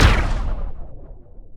poly_explosion_plasma.wav